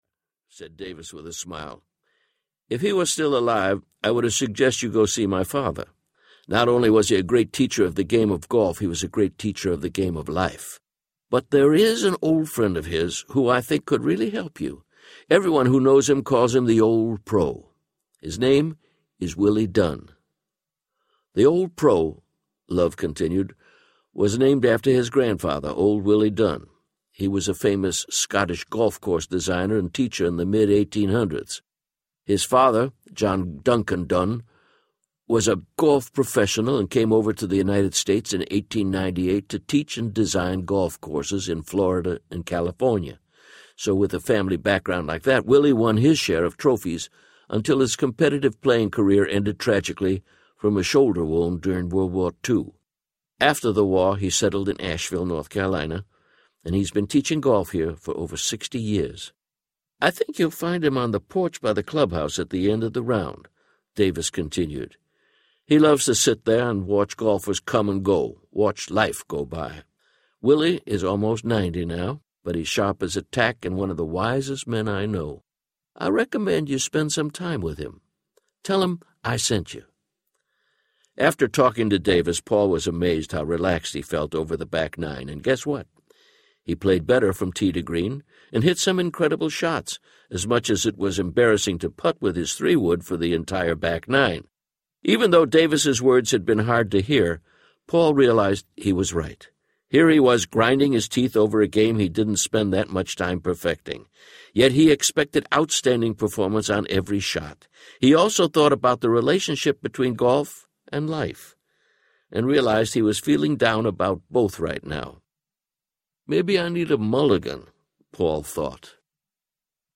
Narrator
2.86 Hrs. – Unabridged